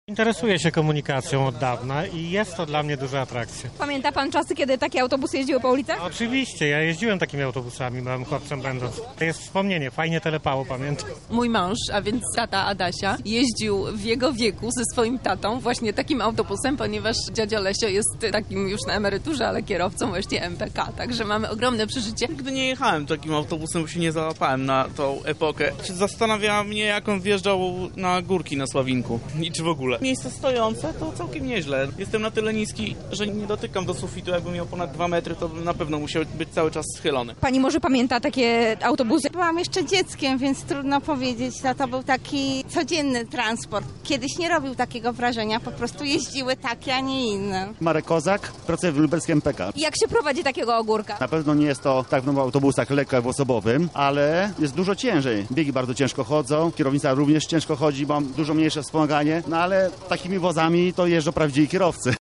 Przejazd historycznym autobusem odbył się w ramach Europejskiego Tygodnia Zrównoważonego Transportu.